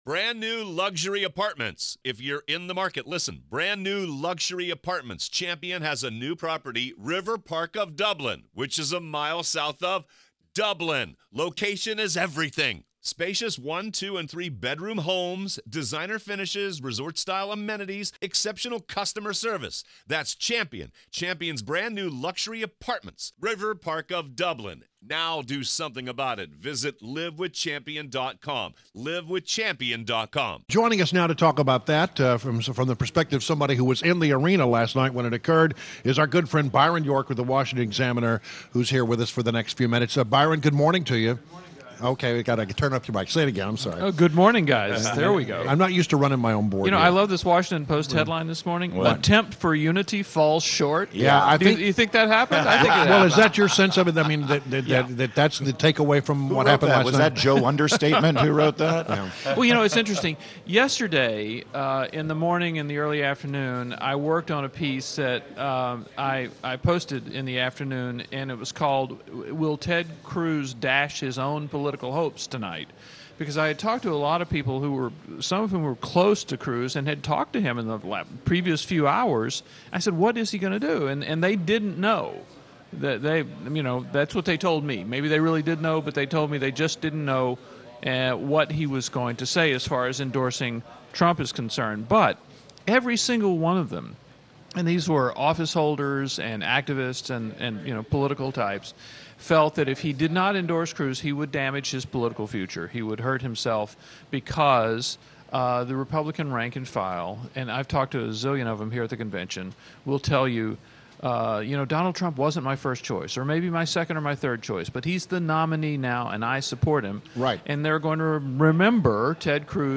WMAL Interview - Byron York 07.21.16